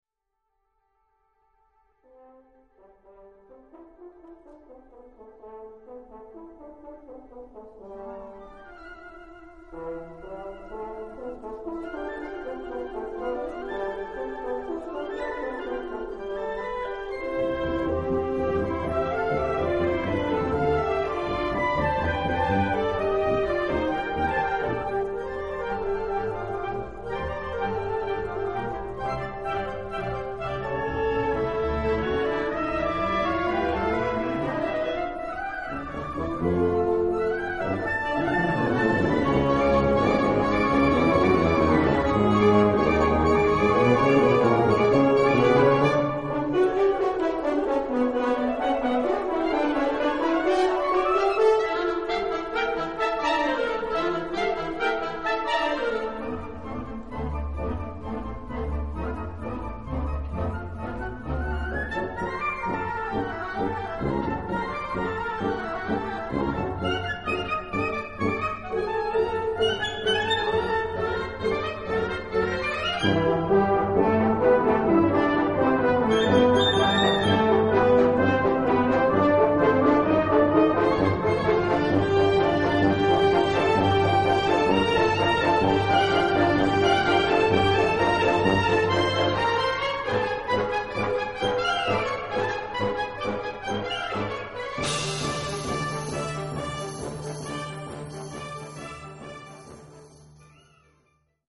Dubbel cd met historische opnames
harmonieorkest
27 november 2005 – Orangerie Roermond